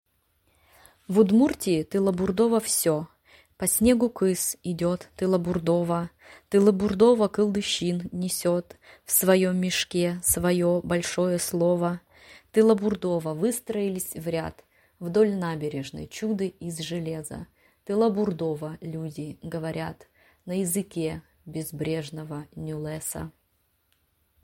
читает стихотворение «В Удмуртии тылобурдово всё…»